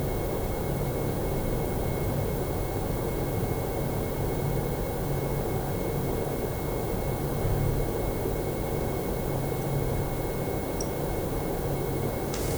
A ringing sound at 2.2 kHz. I have the Studio Ultra.